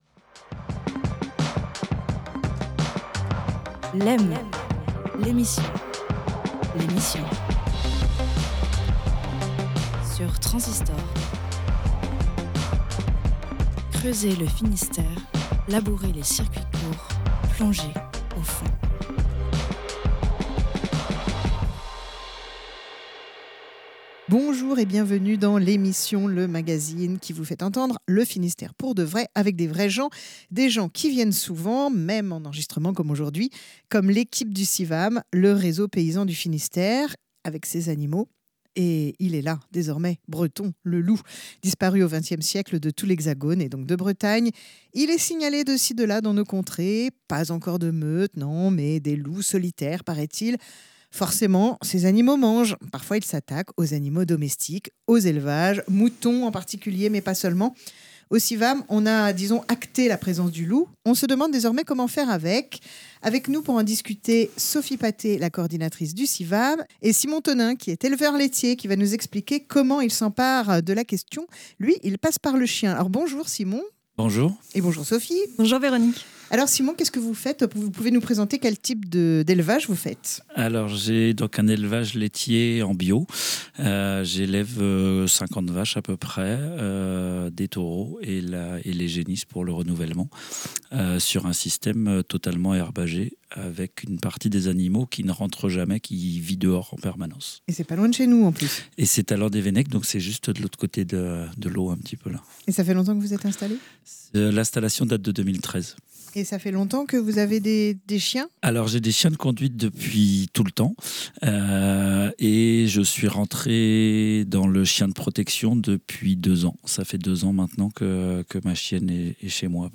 Un éleveur bovin du réseau paysan Civam du Finistère fait part de son expérience du chien de protection des troupeaux.